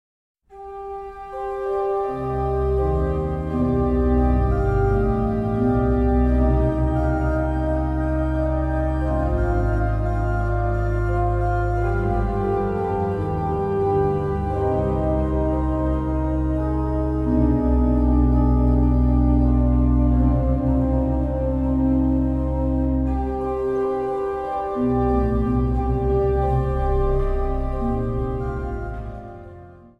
Instrumentaal
Zang